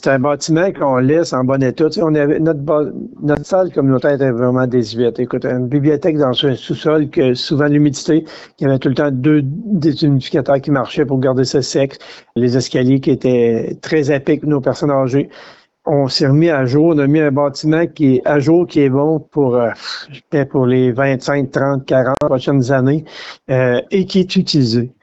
Lors de son entrevue, le maire Lyonnais a parlé d’un héritage important pour la Municipalité. https